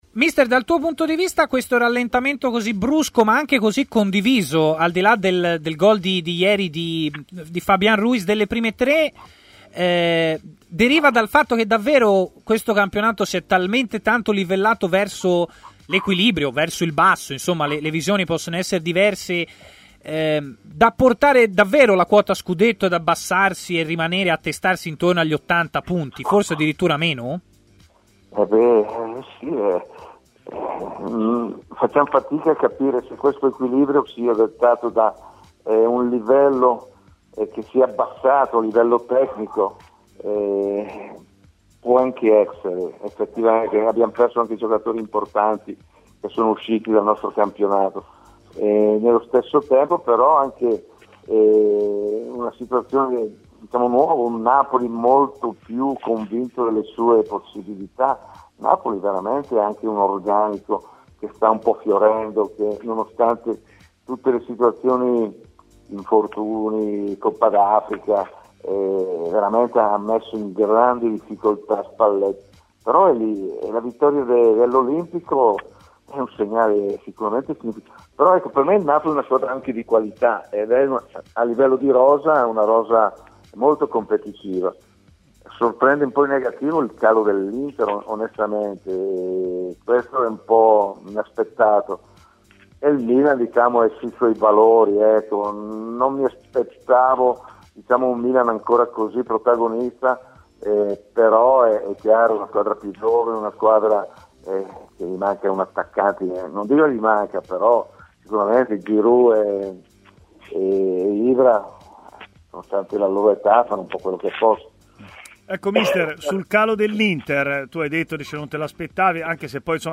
L'allenatore Bortolo Mutti ha parlato in diretta su TMW Radio, durante la trasmissione Stadio Aperto.